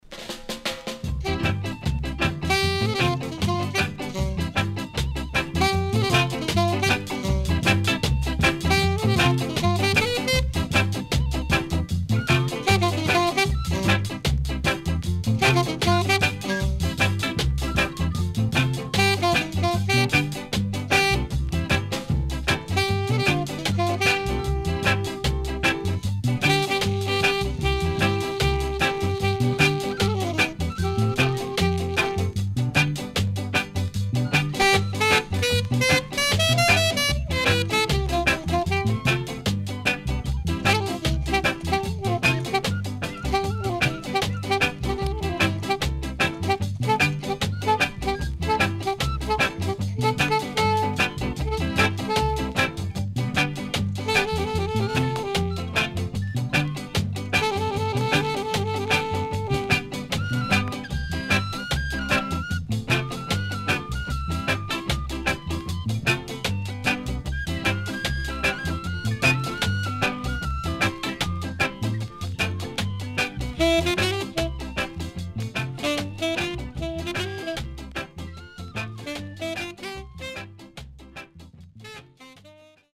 HOME > REGGAE / ROOTS  >  EARLY REGGAE
SIDE A:うすいこまかい傷ありますがノイズあまり目立ちません。